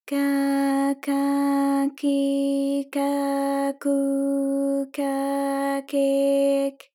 ALYS-DB-001-JPN - First Japanese UTAU vocal library of ALYS.
ka_ka_ki_ka_ku_ka_ke_k.wav